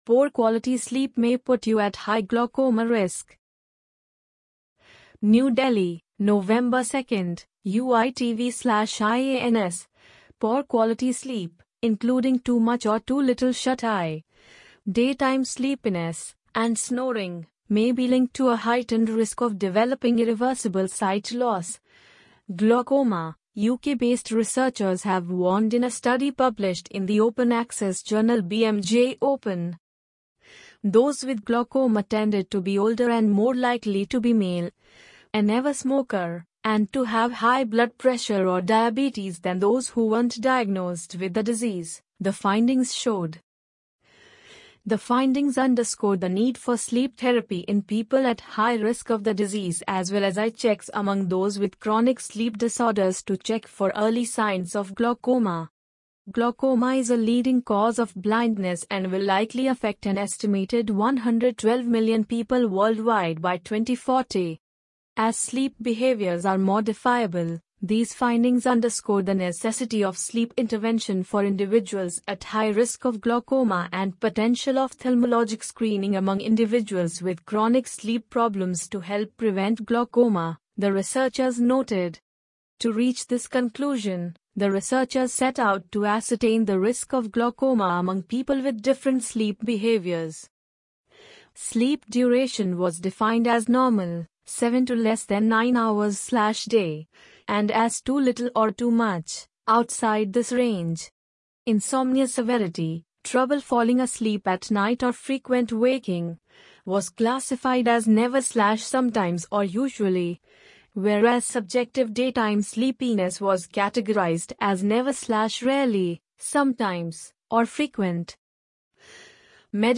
amazon_polly_14289.mp3